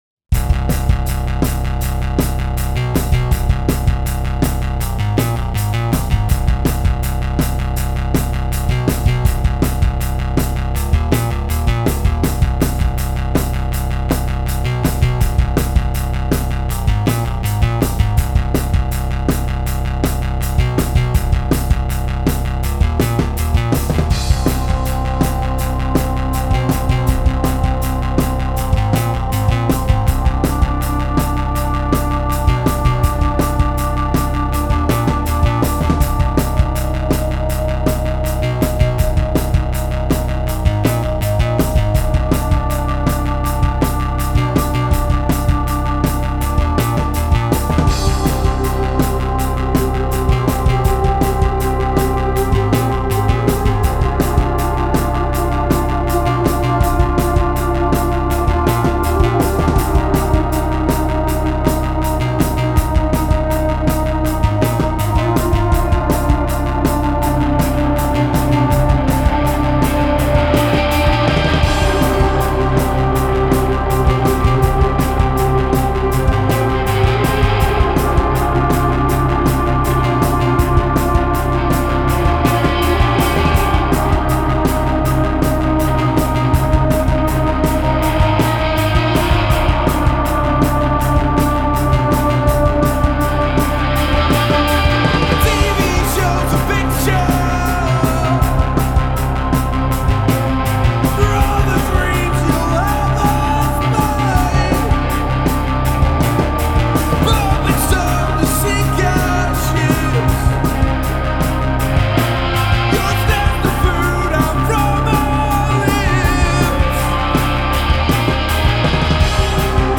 *фоновая композиция –